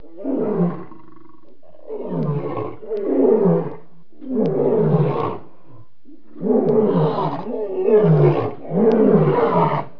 جلوه های صوتی
دانلود صدای حیوانات جنگلی 103 از ساعد نیوز با لینک مستقیم و کیفیت بالا